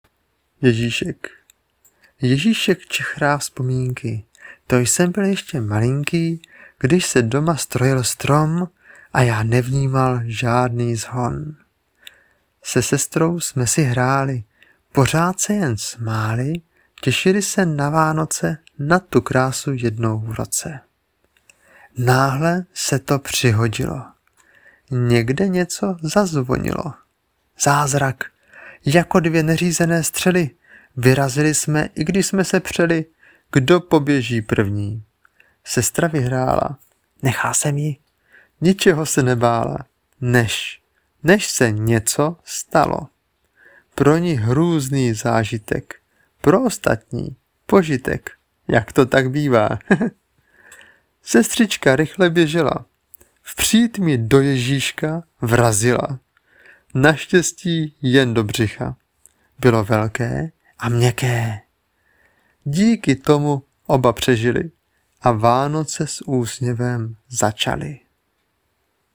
Krásně namluvené.
Hezký doprovod mluvený i zpěvný.